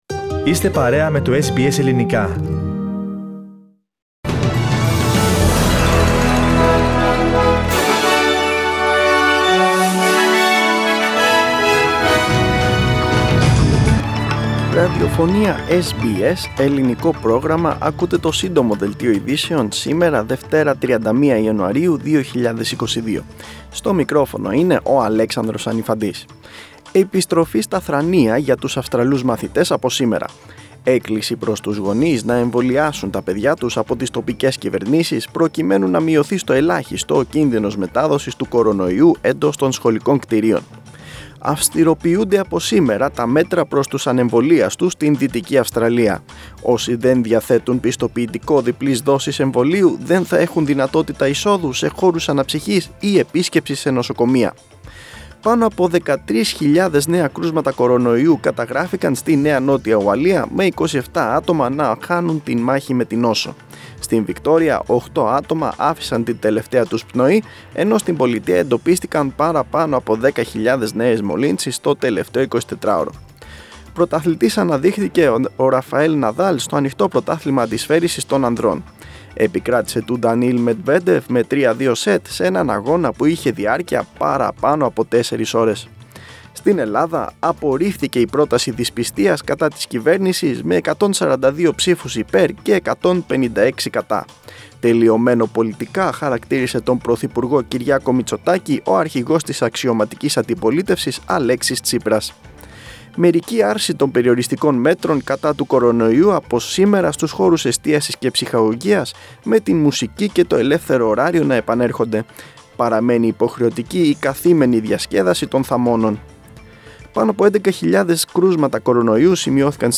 News Flash - Σύντομο Δελτίο
Source: SBS Radio